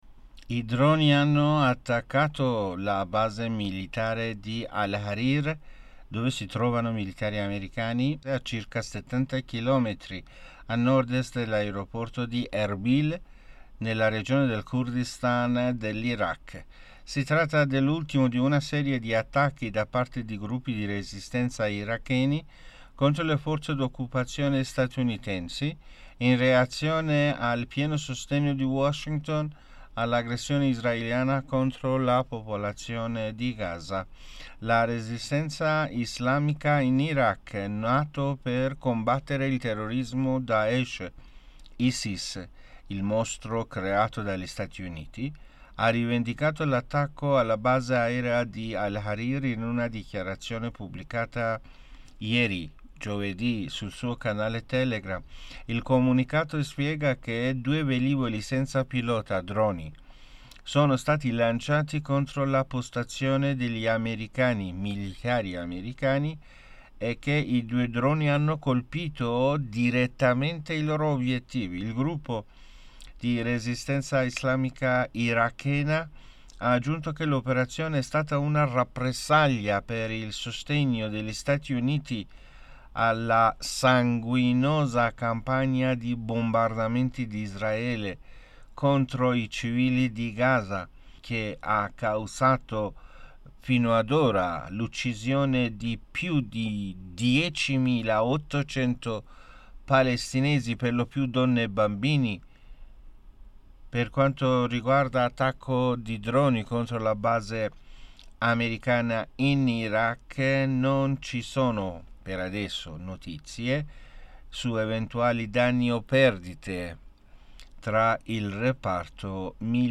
Notiziario